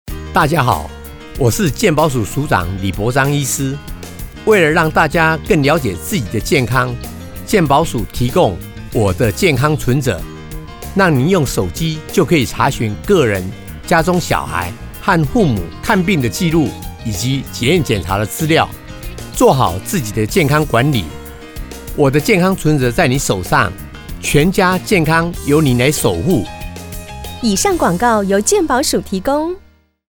廣播